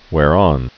(wâr-ŏn, -ôn, hwâr-)